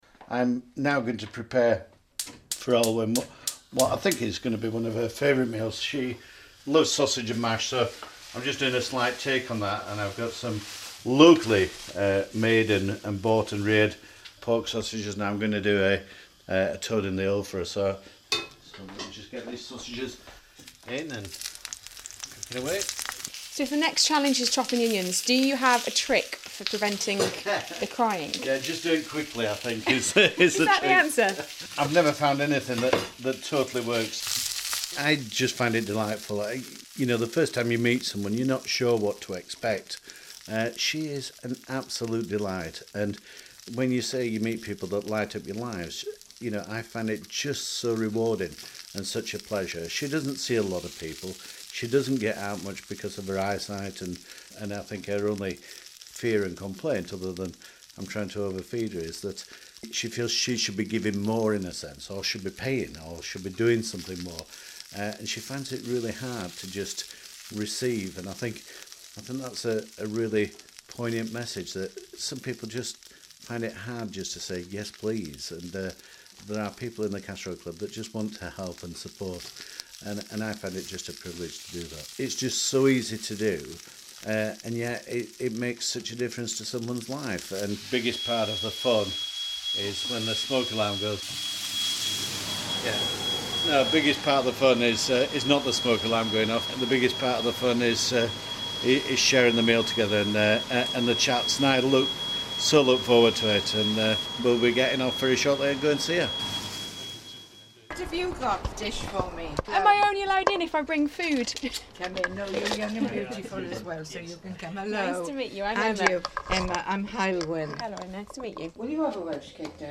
(Broadcast on BBC Radio Stoke, November 2015)